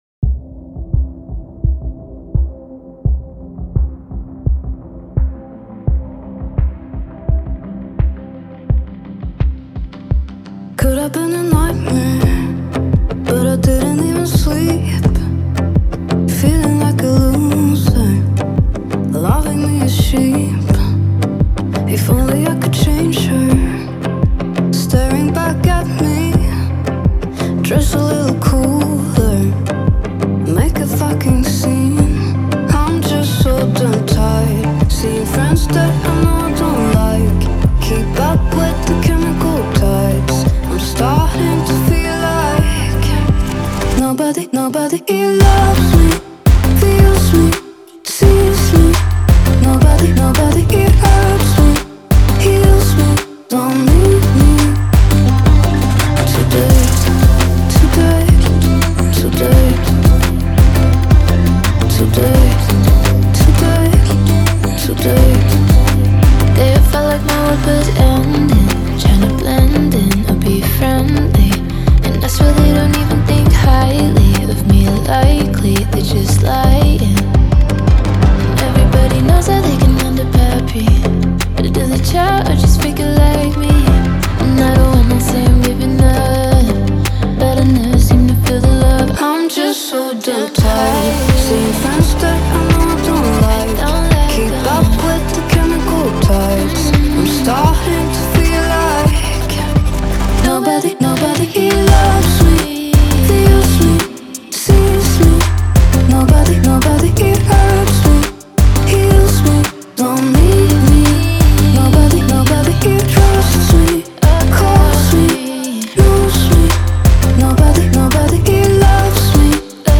это трек в жанре поп с элементами инди